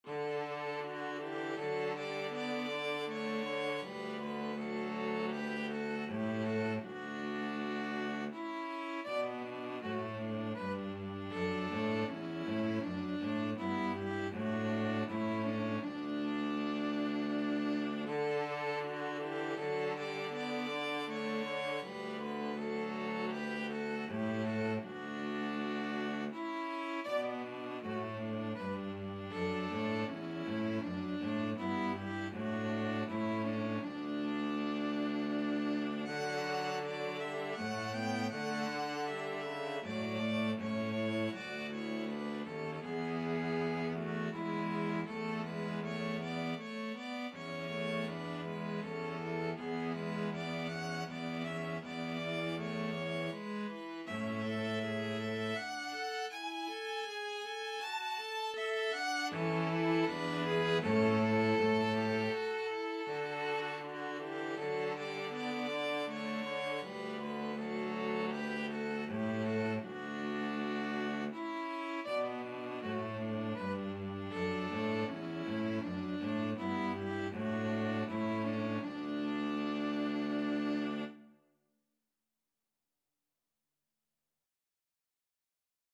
ViolinViolaCello
D major (Sounding Pitch) (View more D major Music for String trio )
Andante
String trio  (View more Easy String trio Music)
Classical (View more Classical String trio Music)